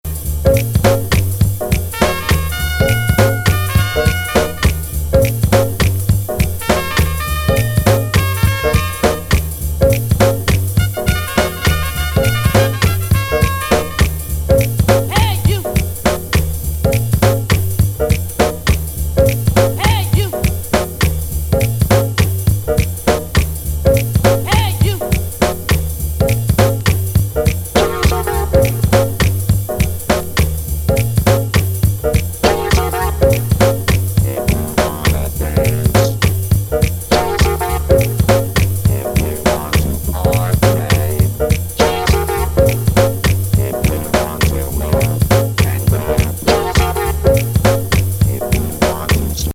アーリー90’S NYアングラ・ハウス。エレクトロ808ビートのスムース・ジャズ・